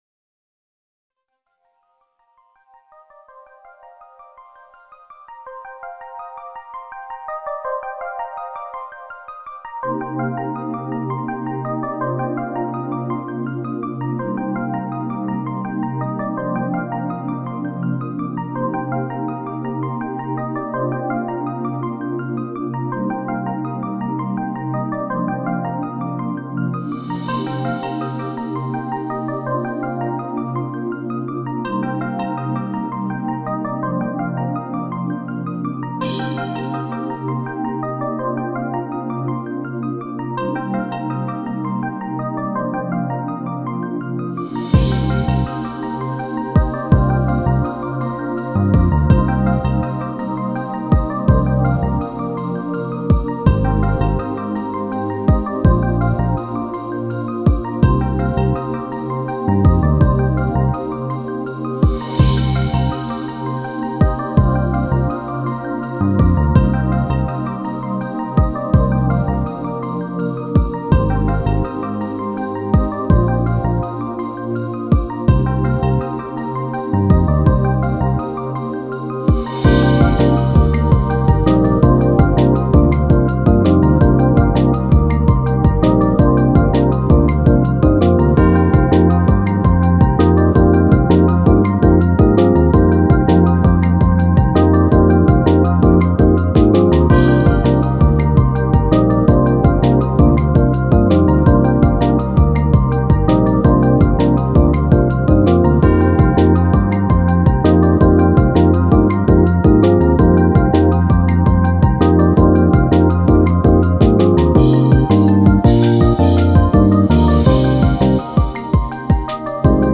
遠い昔の記憶を回想するような切ない曲です。
ピアノの伴奏とバイオリンがメロディを奏でます。...